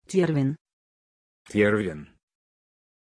Aussprache von Tjorven
pronunciation-tjorven-ru.mp3